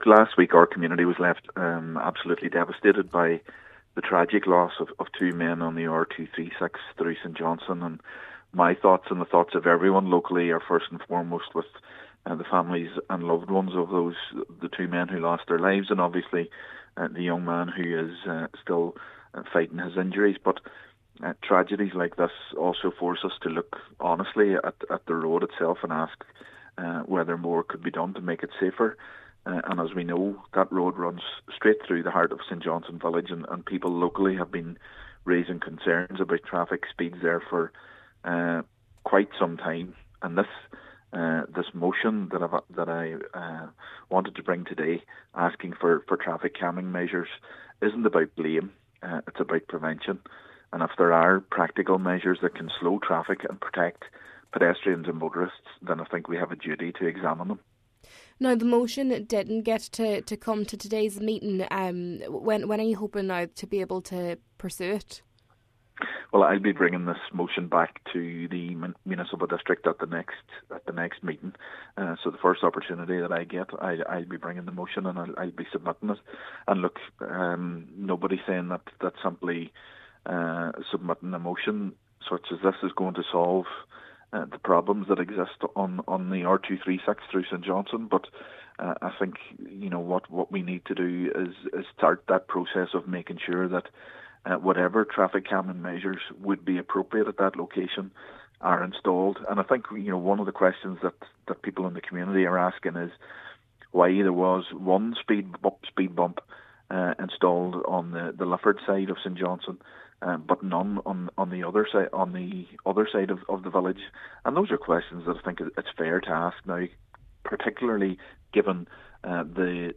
He wants to see action taken and regrets that the motion could not be progressed today: